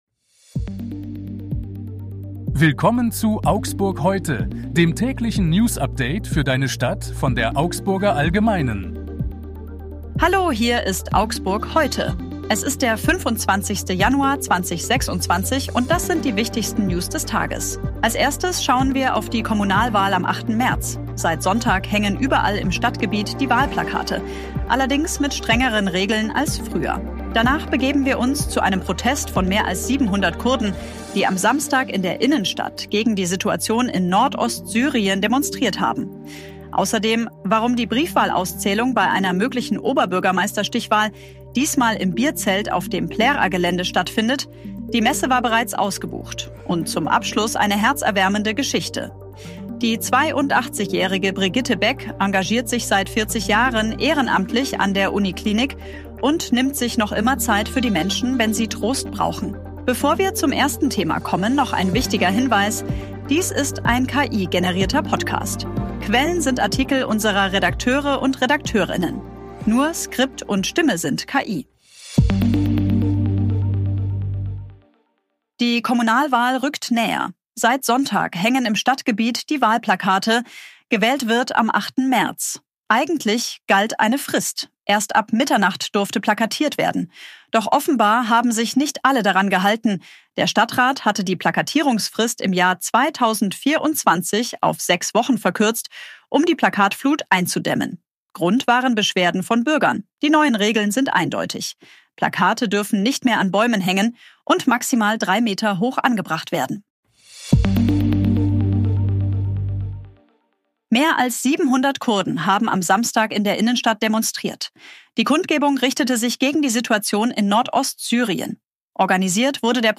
Hier ist das tägliche Newsupdate für deine Stadt.
Menschen Dies ist ein KI-generierter Podcast.